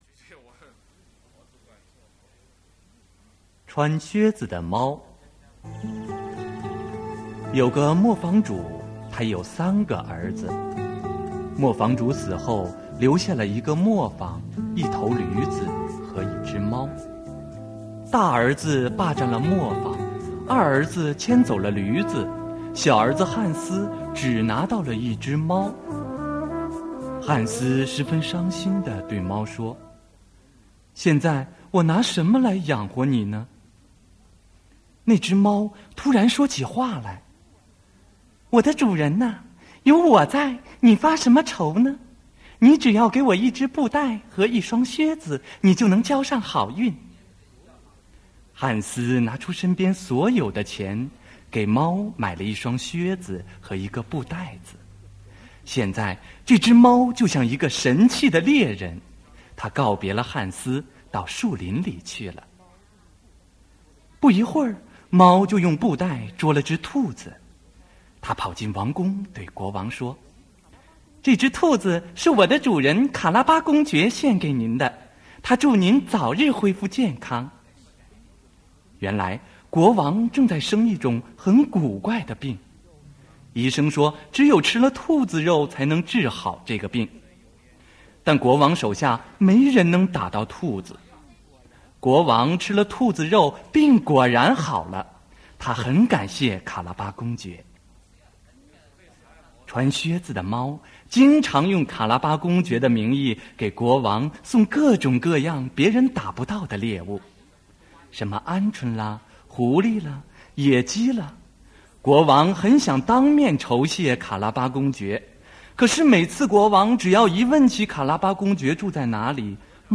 Audiobook «Puss in Boots» in Chinese (穿靴子的猫)